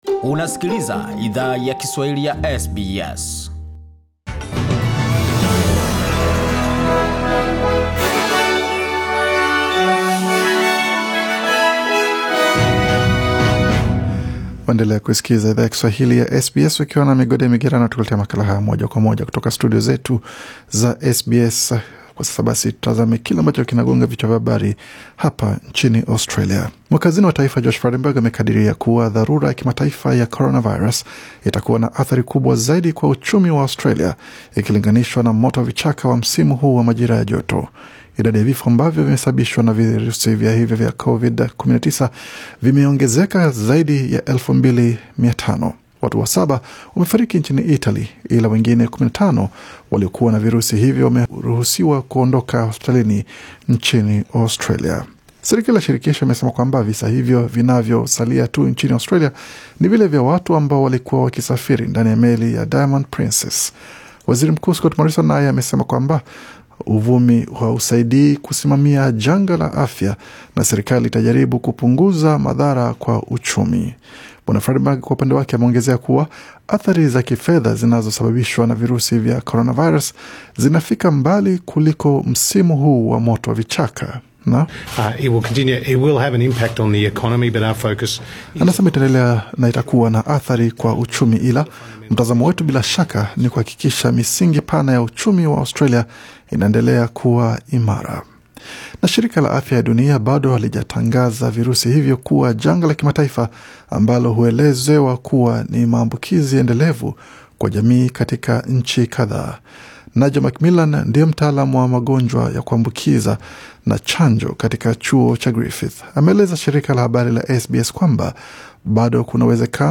Taarifa za habari: Erick Kabendera aachiwa huru